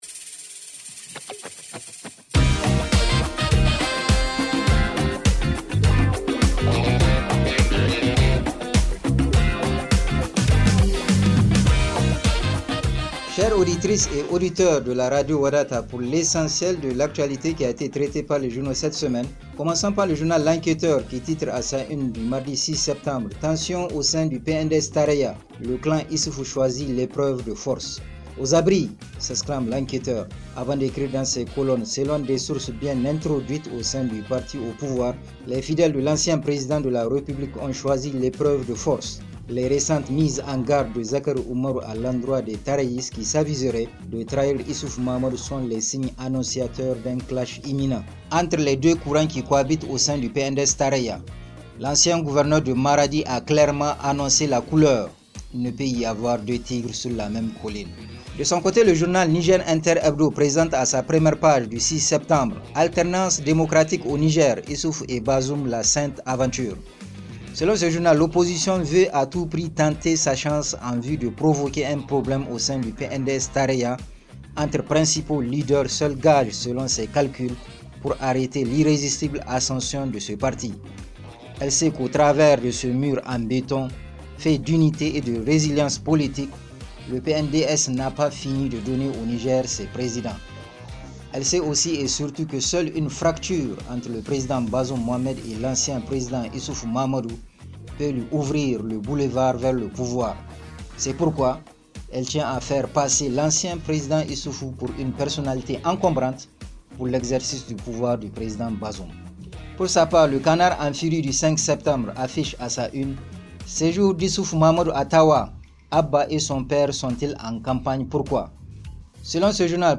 Revue de presse en français